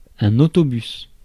Ääntäminen
IPA : /bʌs/